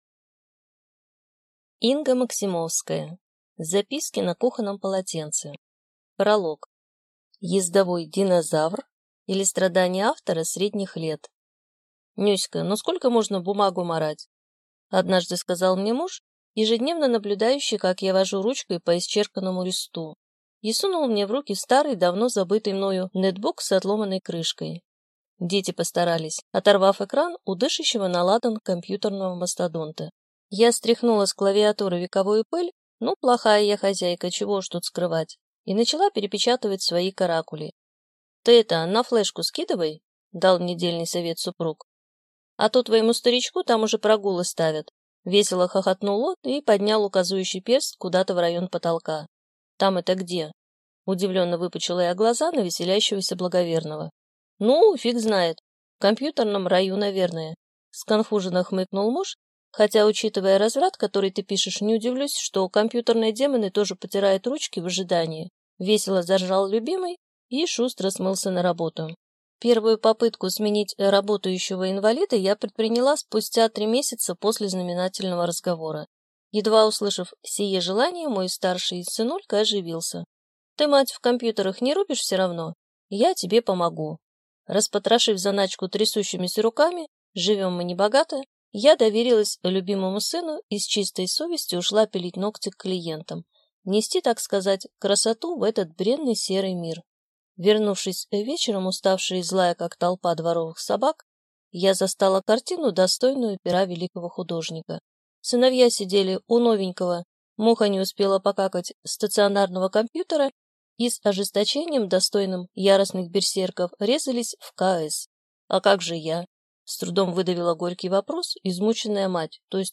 Аудиокнига Записки на кухонном полотенце | Библиотека аудиокниг